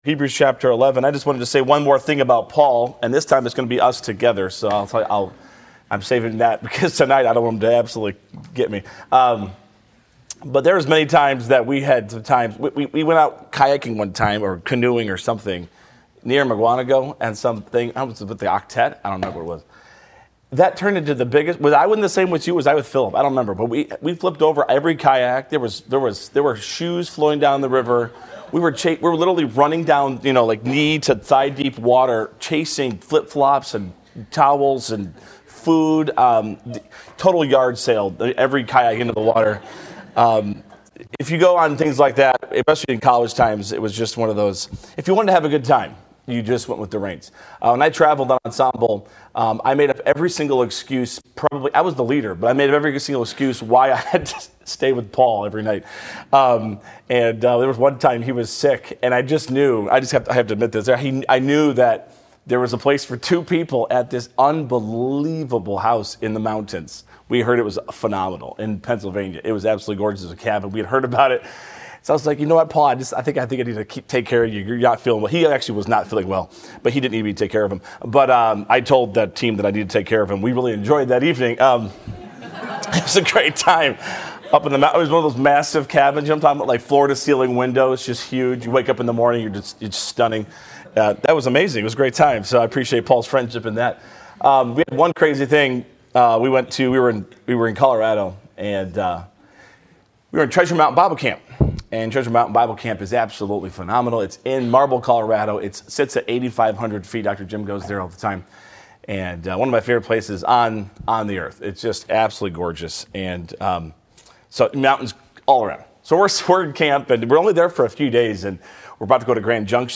2025-2026 BCM Chapel